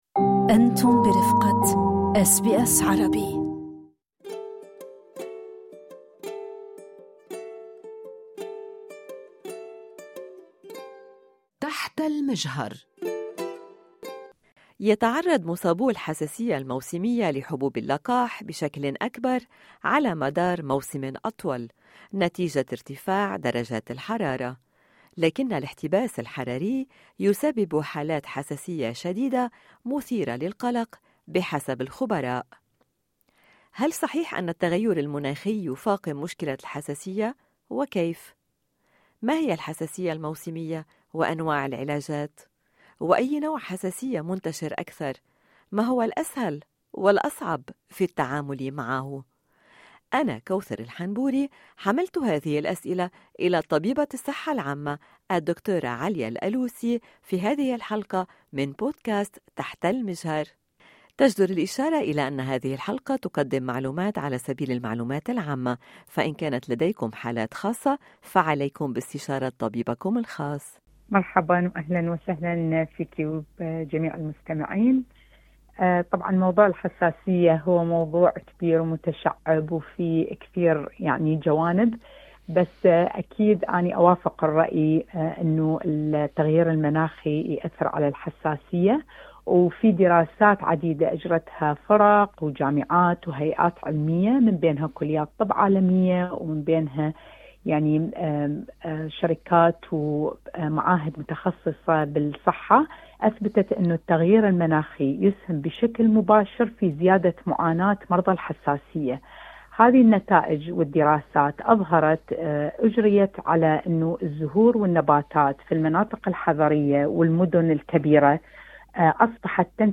اشارة الى أن هذا اللقاء يقدم معلومات عامة فقط لمزيد من التفاصيل عن حالات خاصة عليكم باستشارة طبيبكم الخاص أعلنت أولا أنها توافق على أن التغيير المناخي يؤثر سلبا على الحساسية مشيرة الى وجود دراسات عديدة أجرتها جامعات وهيئات علمية بينها كليات طب ومعاهد متخصصة بالصحة أثبتت ذلك.